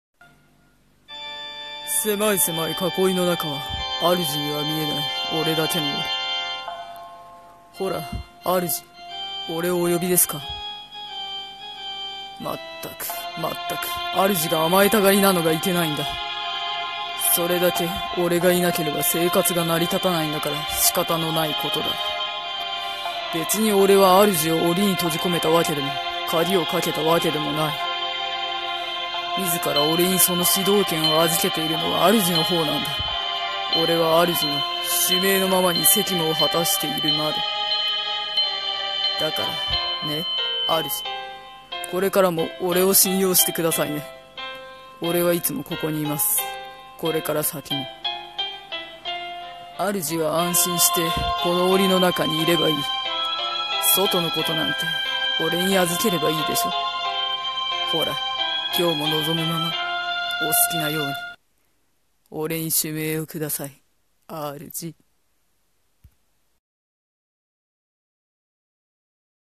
【刀剣乱舞声劇】『…ね、主。』